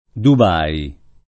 vai all'elenco alfabetico delle voci ingrandisci il carattere 100% rimpicciolisci il carattere stampa invia tramite posta elettronica codividi su Facebook Dubay [ar. dub # i ] top. m. (Ar.) — italianizz. Dubai [ dub # i ]